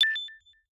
EmailIncoming.ogg